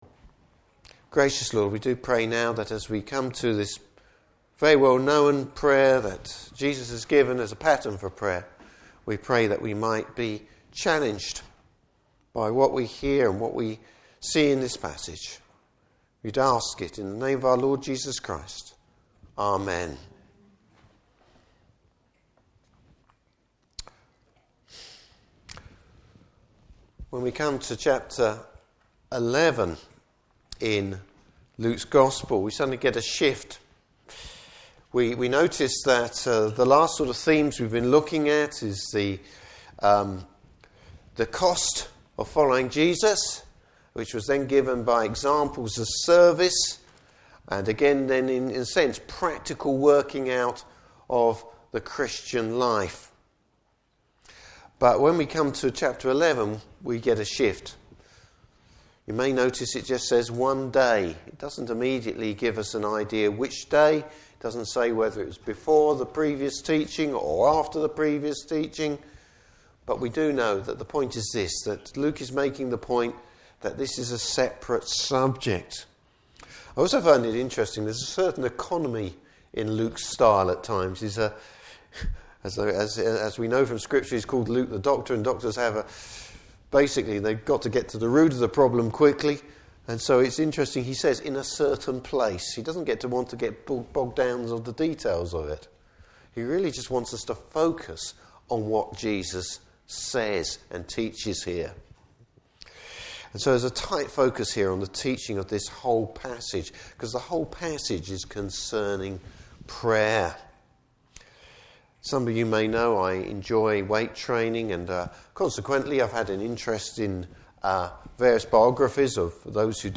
Service Type: Morning Service Bible Text: Luke 11:1-13.